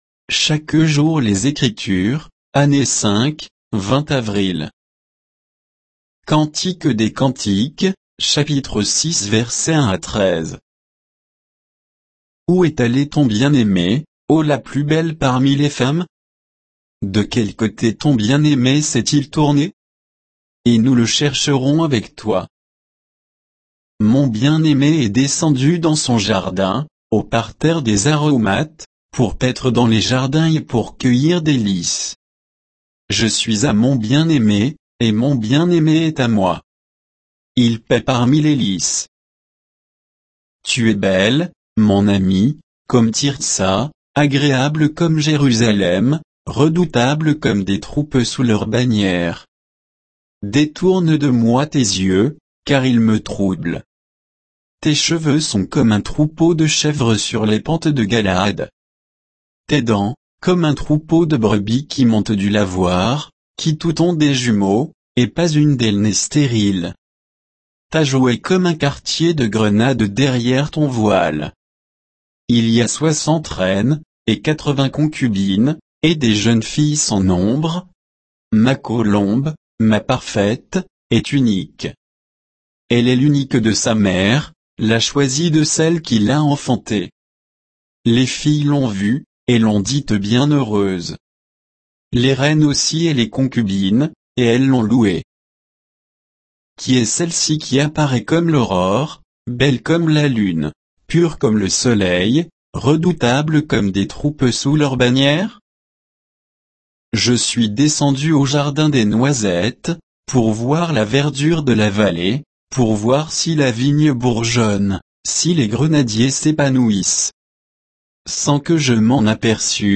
Méditation quoditienne de Chaque jour les Écritures sur Cantique des cantiques 6